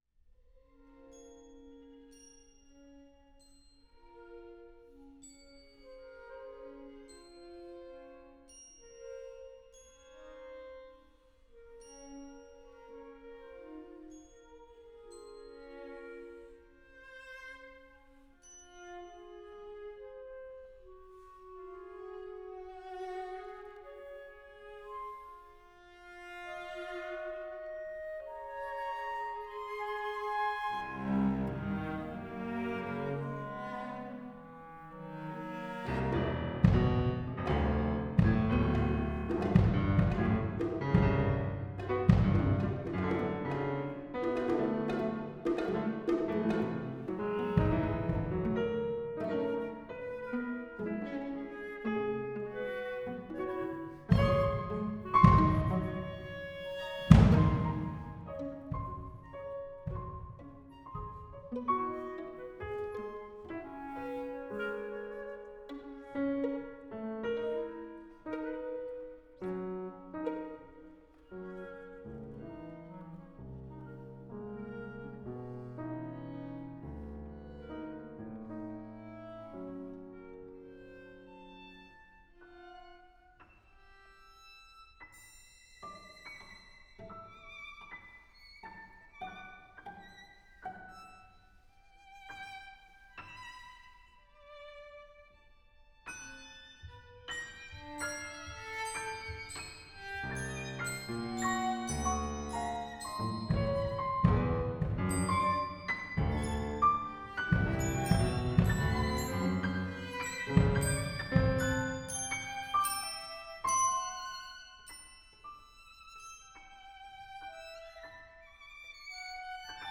For flute, clarinet, violin, viola, cello, piano, and percussion
Beginning with the ever-moving permeation of dappled light through clouds, the piece then moves toward a blinding winter brilliance and ends with an exuberant dance of summer sunshine (my favorite).